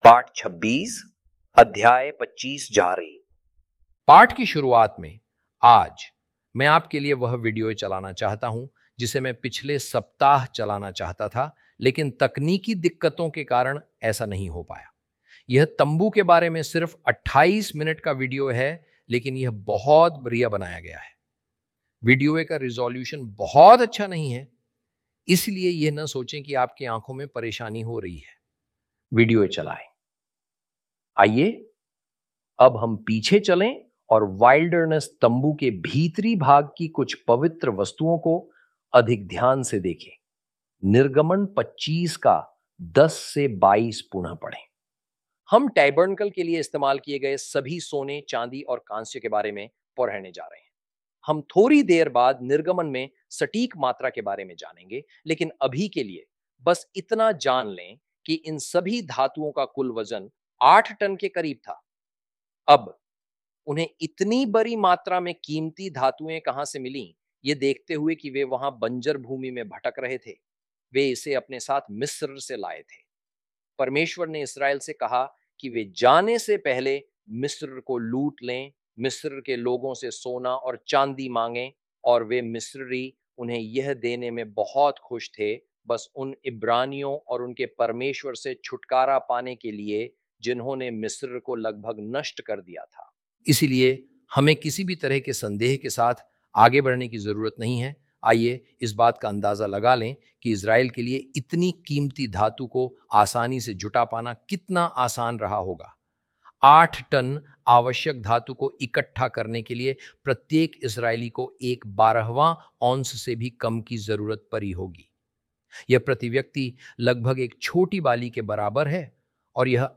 Video, audio and textual lessons